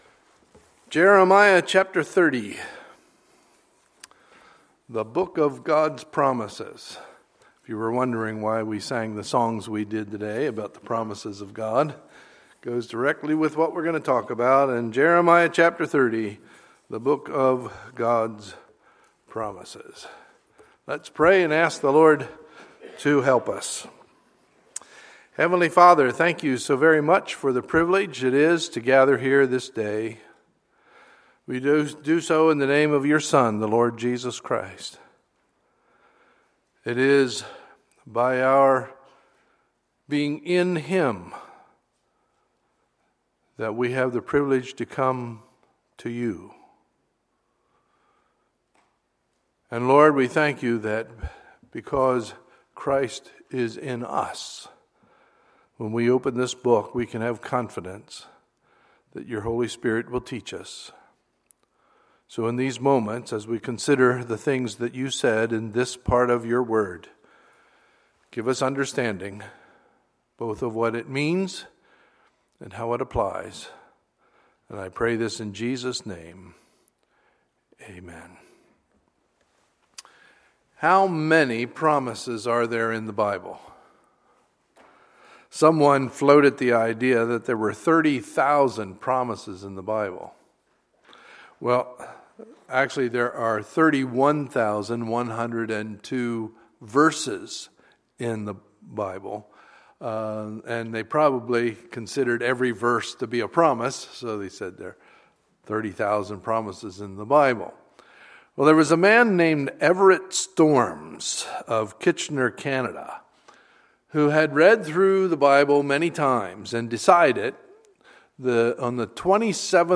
Sunday, July 26, 2015 – Sunday Morning Service
Sermons